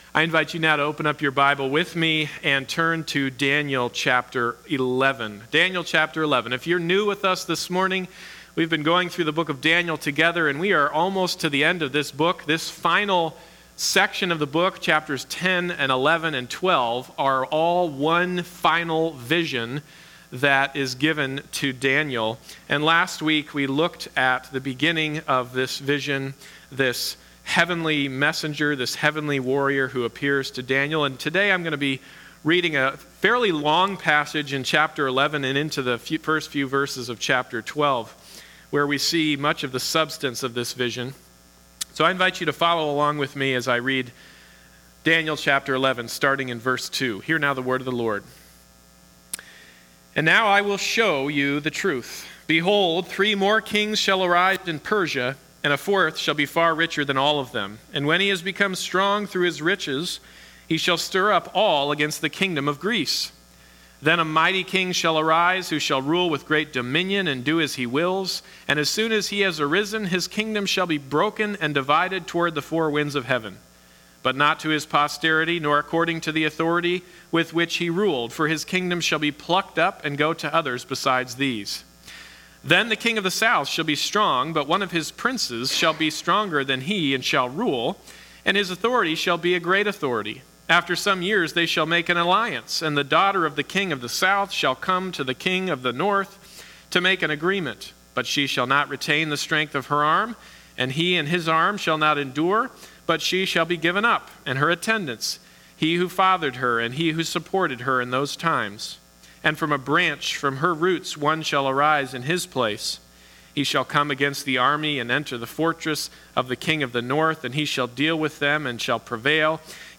Daniel 11:2-12:3 Service Type: Sunday Morning Service « Unveiling a Cosmic Conflict How Long Till the End?